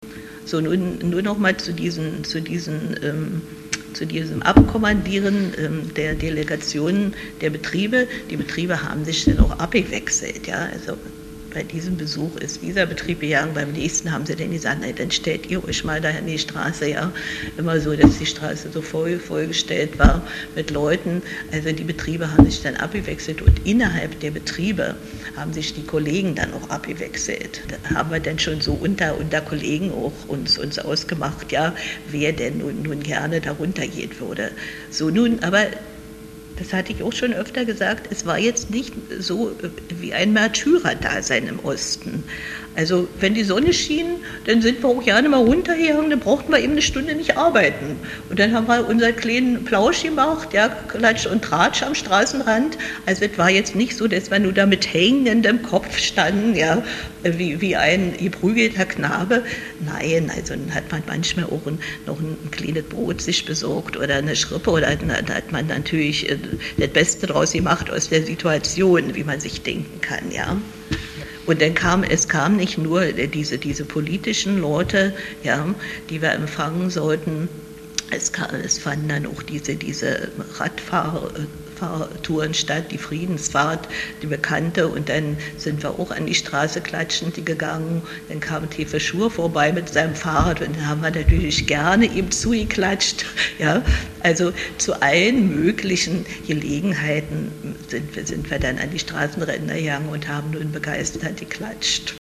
Obwohl beide unzufrieden mit der politischen Führung der DDR waren, zeigten sie in einem Interview auf Fragen zum Kennedy-Besuch sehr unterschiedliche Meinungen.